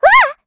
Estelle-Vox_Attack6_kr.wav